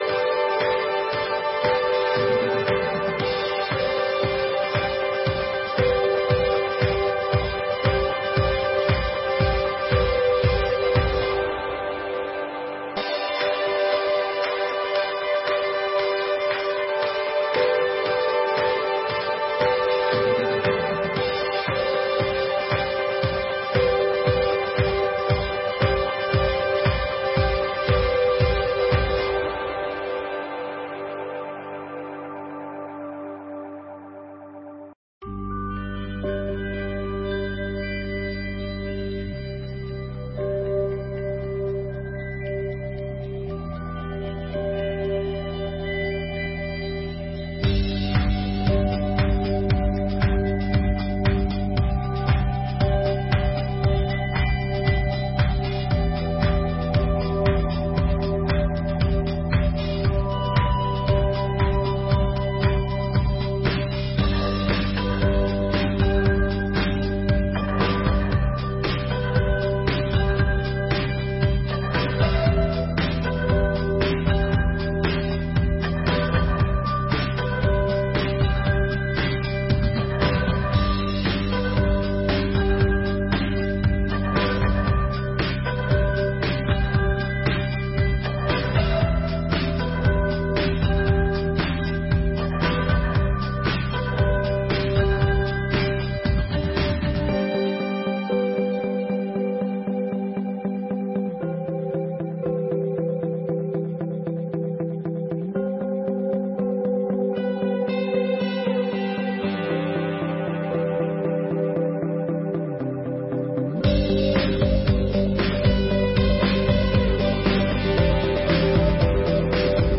Sessões Solenes de 2024